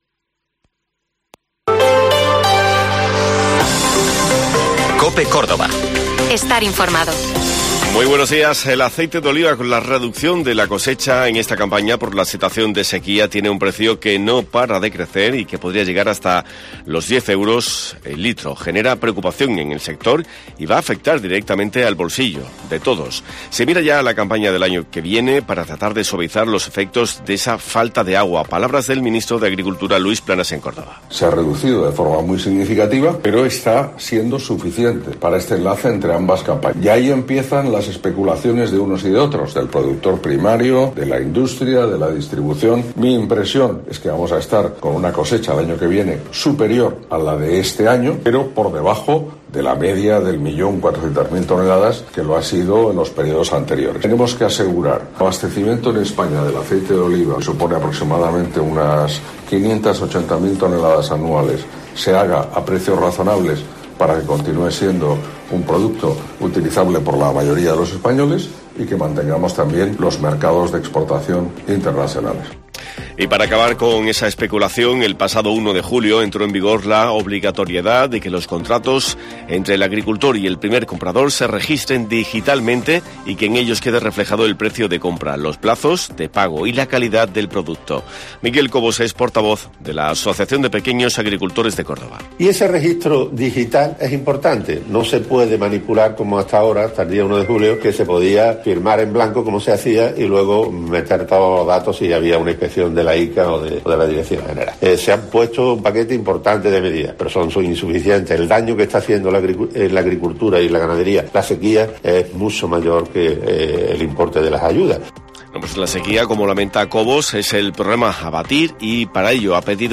Informativo Herrera en COPE Córdoba